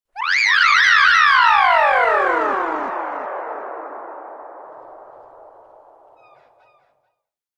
Звуки мутаций